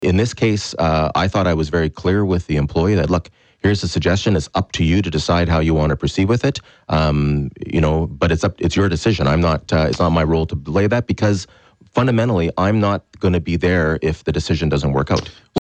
Mitch-Panciuk.mp3